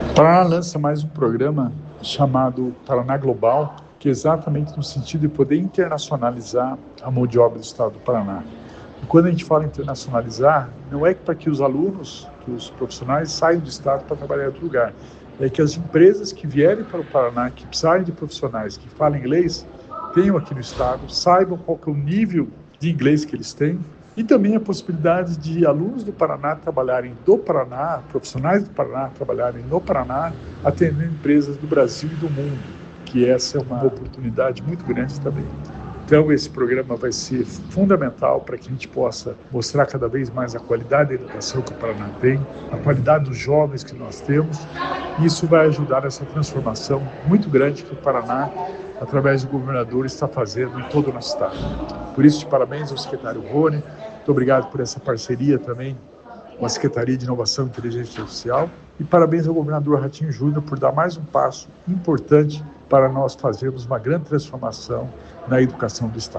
Sonora do secretário da Inovação e Inteligência Artificial, Alex Canziani, sobre a implementação do programa Paraná Global